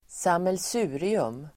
Uttal: [²samels'u:rium]